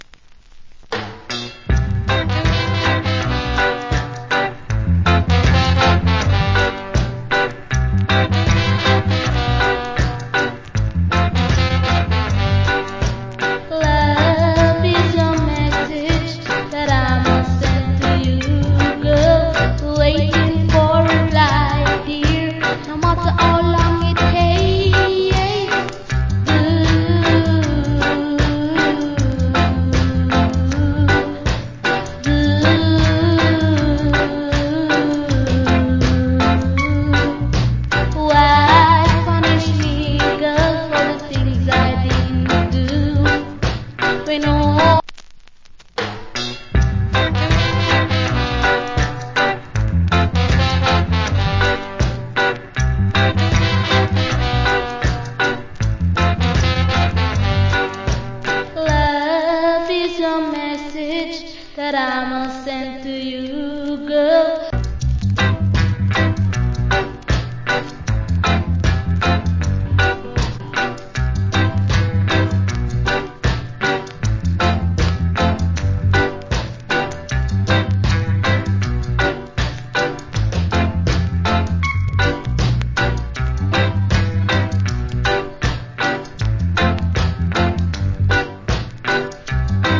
Good Rock Steady Vocal.